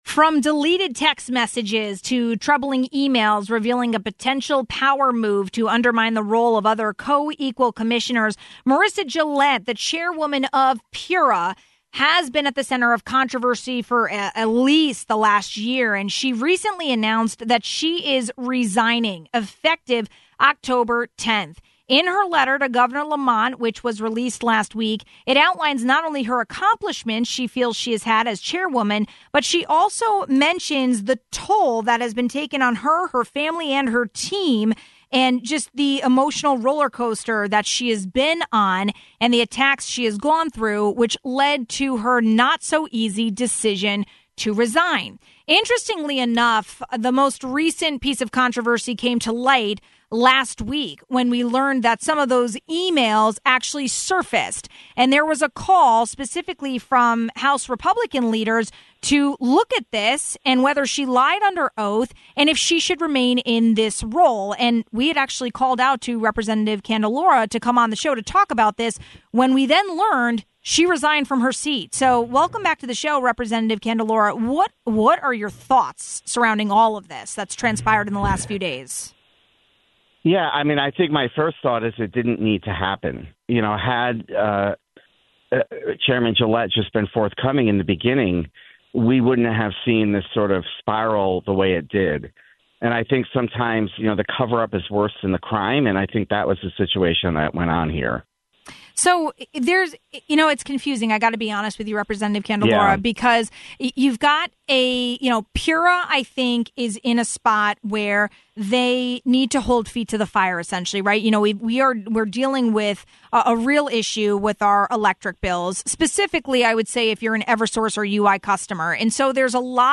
She recently announced she is resigning effective October 10th. Recently, State House Minority Leader, Representative Vincent Candelora, called for Gillett’s impeachment. He joined us with reaction about her resignation announcement and what happens next for PURA.